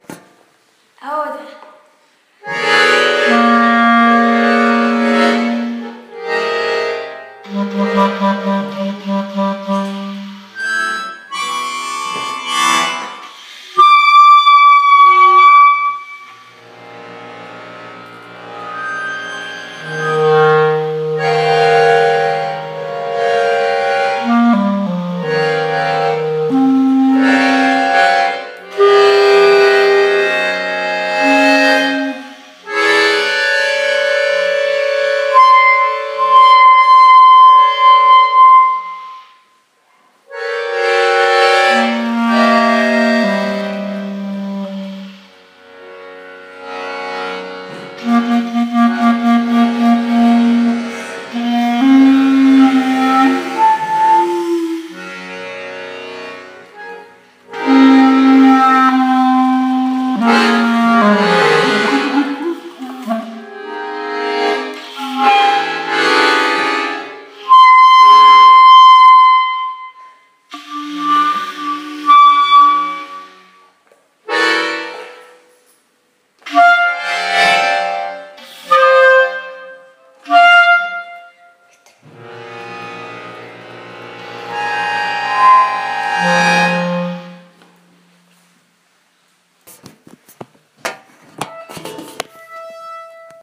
Musicoterapia
Impro-Terapia.m4a